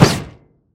Bullet Impact 14.wav